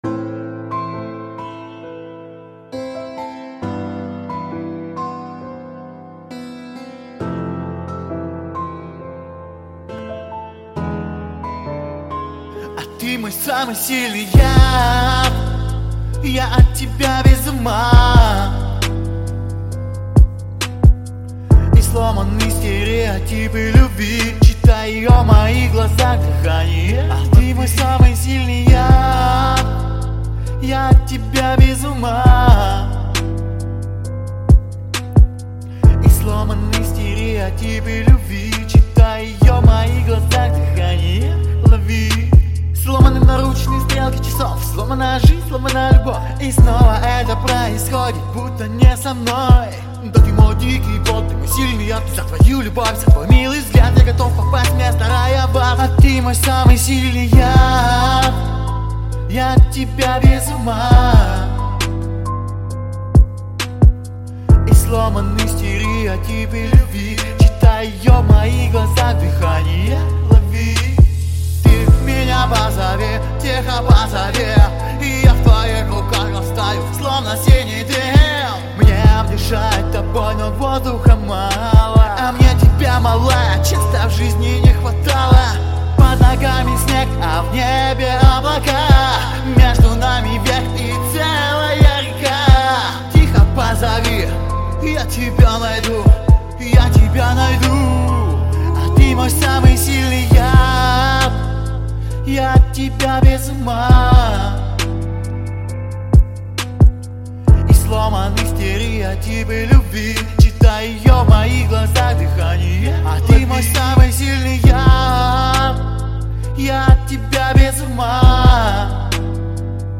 Певческий голос Баритон